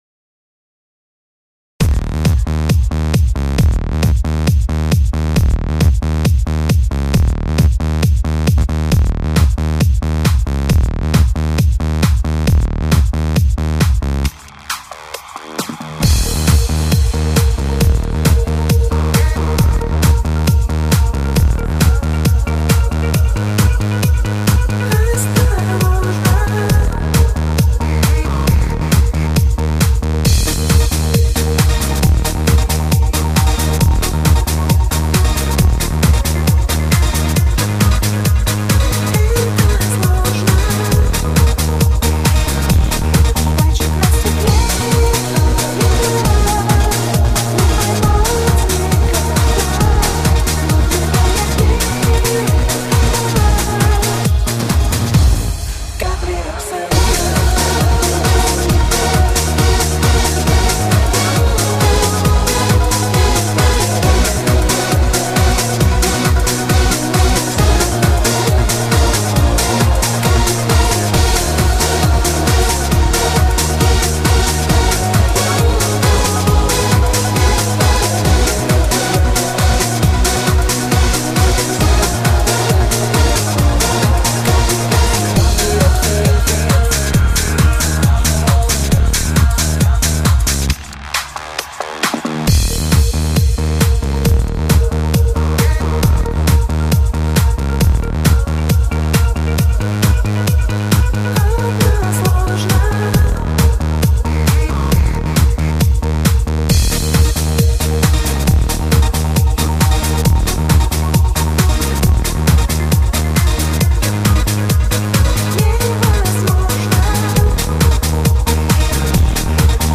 Часто используемые минусовки хорошего качества